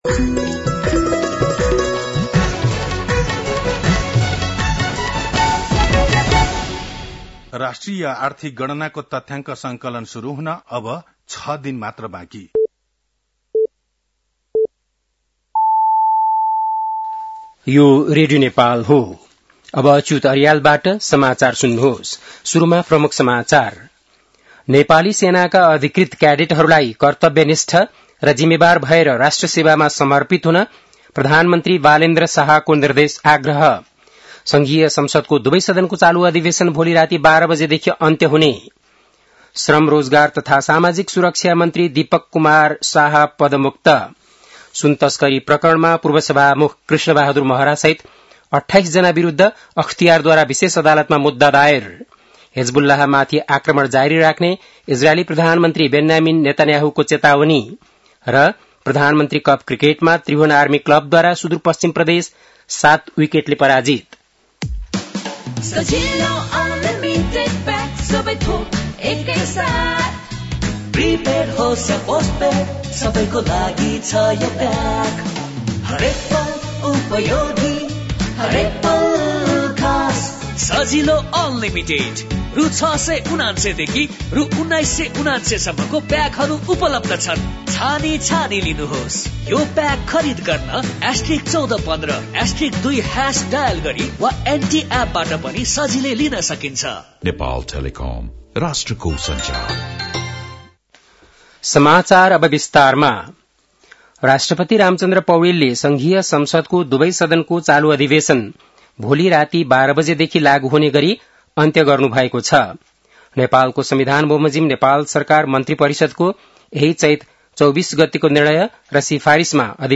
बेलुकी ७ बजेको नेपाली समाचार : २६ चैत , २०८२
7.-pm-nepali-news-1-1.mp3